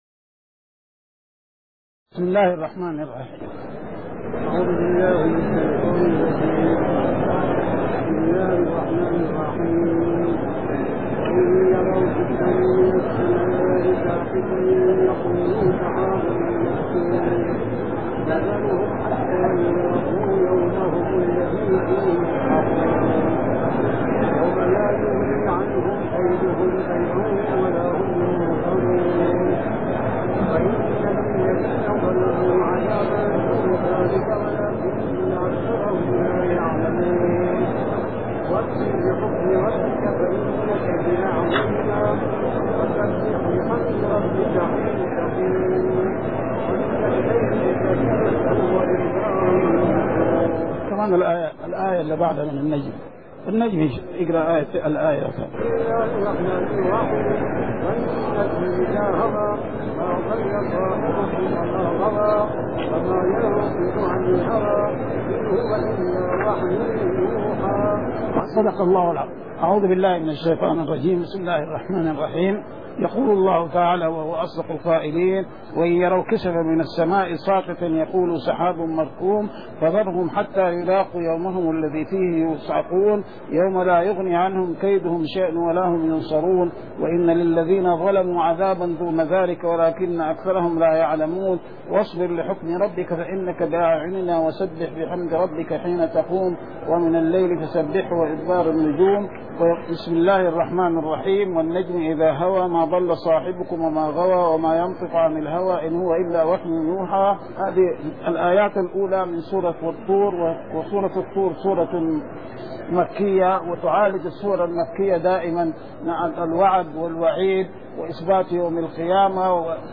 من دروس الحرم المدنى الشريف